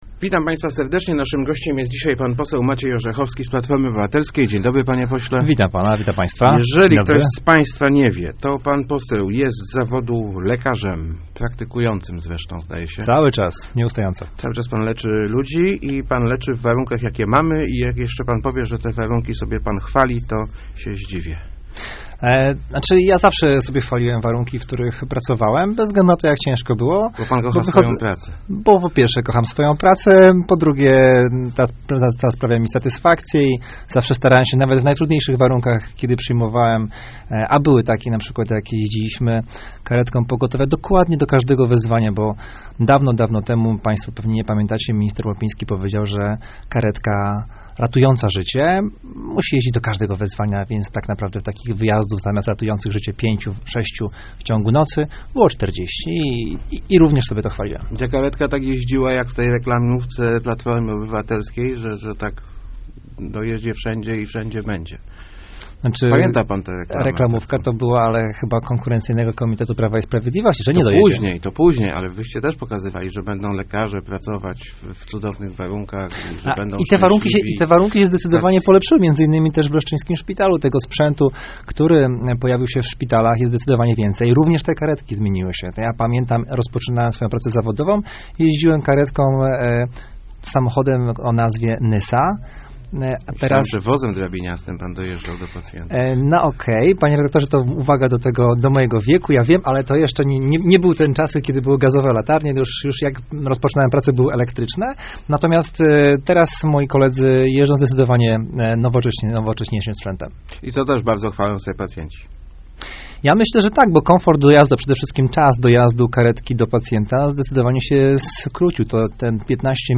Już w przyszłym tygodniu Sejm zajmie się pakietem ustaw zdrowotnych - mówił w Rozmowach Elki poseł Maciej Orzechowski z PO.